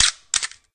camera_click.ogg